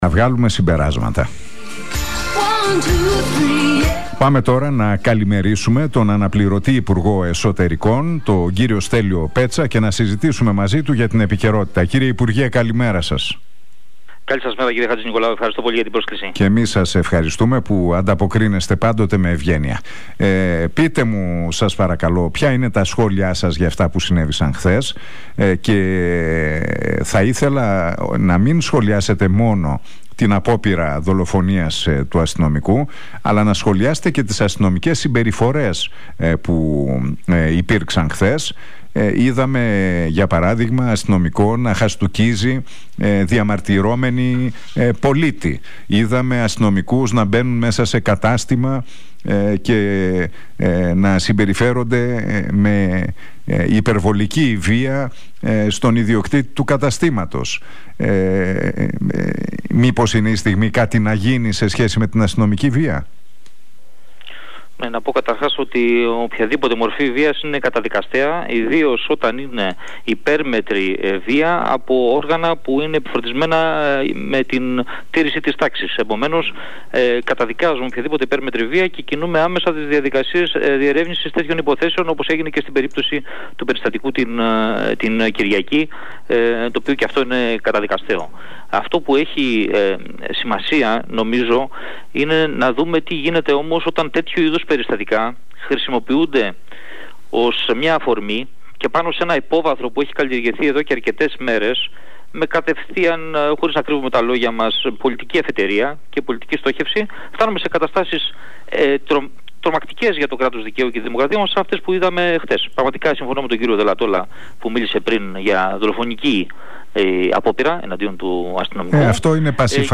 Ο αναπληρωτής υπουργός Εσωτερικών, Στέλιος Πέτσας μιλώντας στην εκπομπή του Νίκου Χατζηνικολάου στον Realfm 97,8...